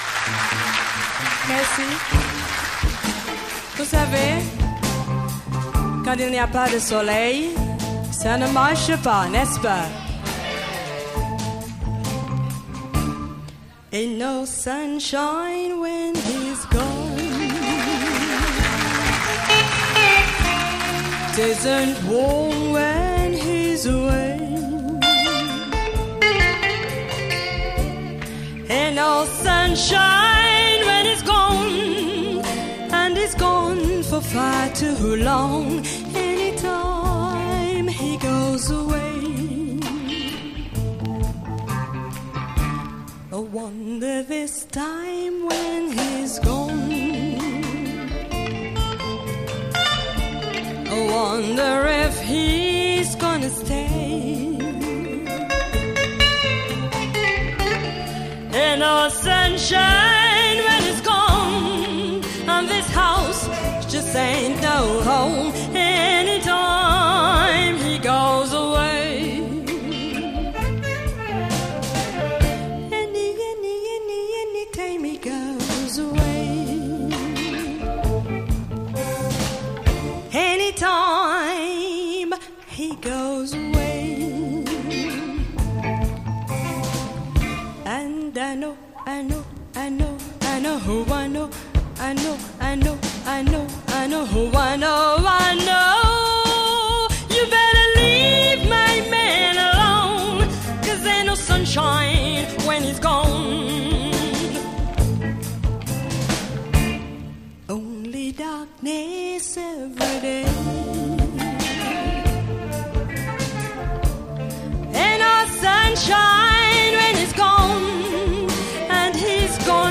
a splendid cover